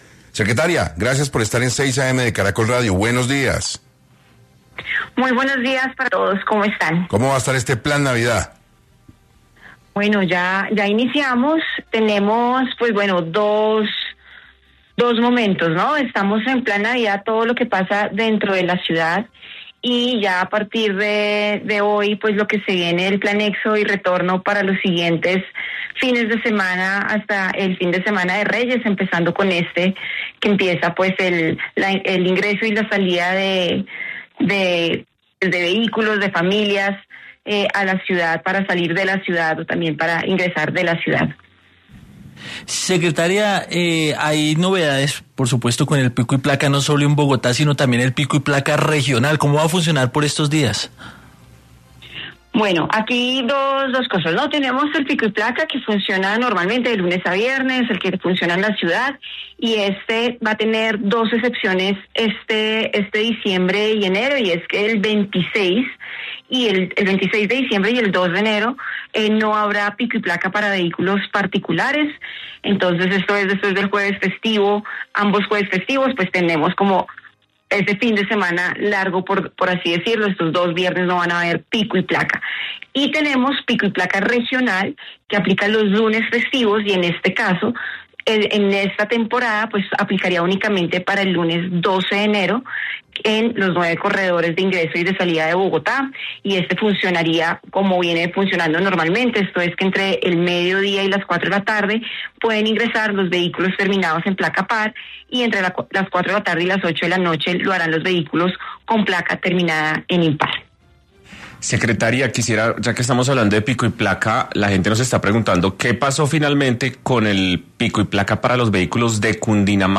En entrevista para 6AM, Claudia Díaz, secretaria distrital de Movilidad, se refirió a las acciones que realizarán las autoridades en Bogotá frente al plan éxodo y el plan retorno que se llevará a cabo en la recta final del 2025: “A partir de este viernes, se viene el Plan Éxodo y Retorno para los próximos fines de semana hasta llegar al Puente de Reyes, donde se espera una alta movilización de vehículos para salir o para ingresar a la ciudad”, manifestó.